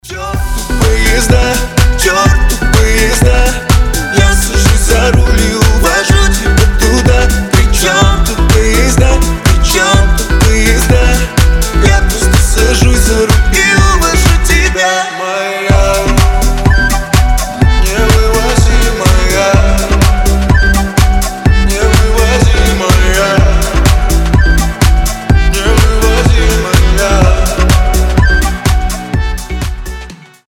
• Качество: 320, Stereo
мужской голос
Club House